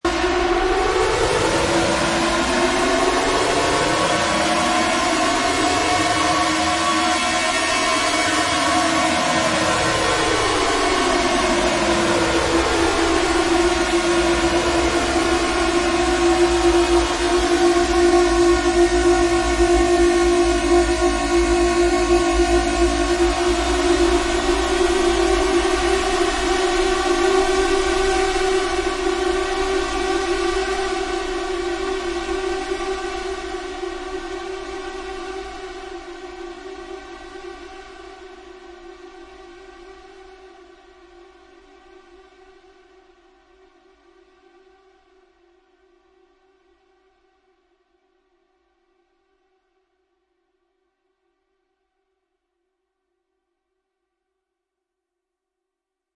Annoying Buzzer Sound Effect Download: Instant Soundboard Button
Annoying Buzzer Sound Button - Free Download & Play